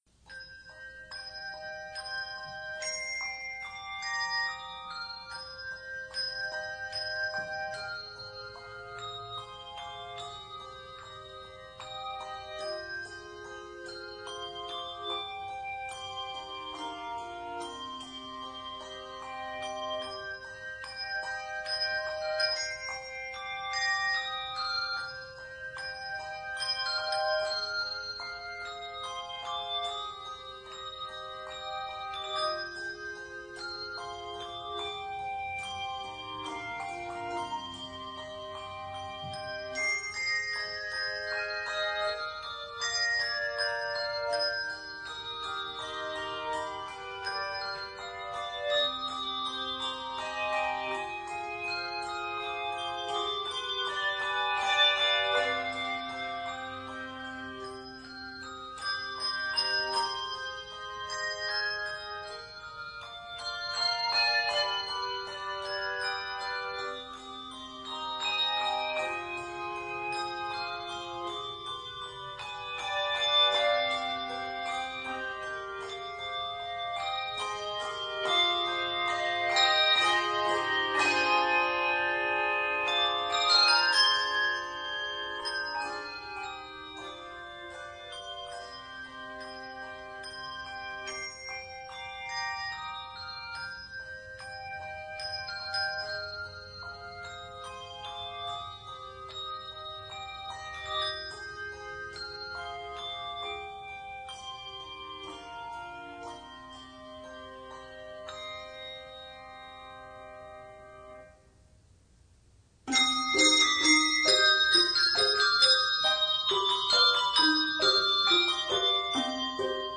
ABA form with coda.
Various bell techniques are used.